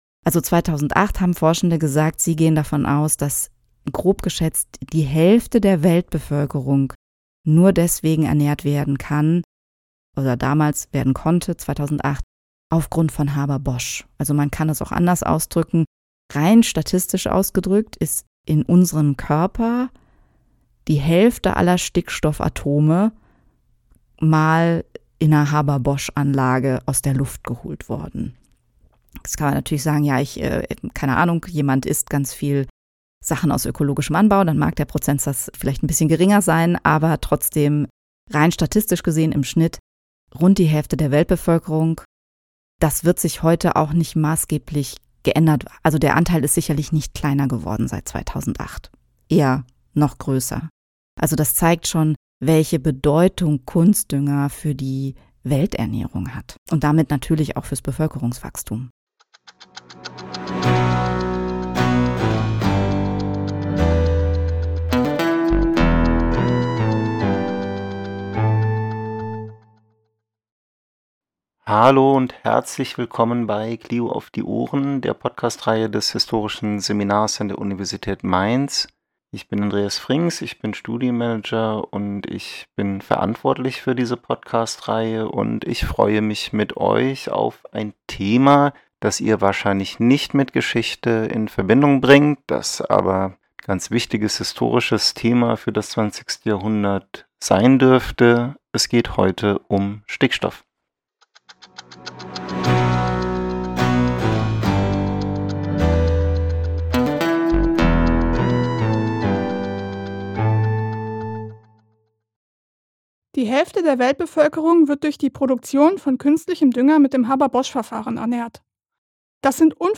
Da sich dieser Podcast auf zwei Zeitebenen bewegt, wurden die Aussagen beider Interviewpartner einander zugeordnet.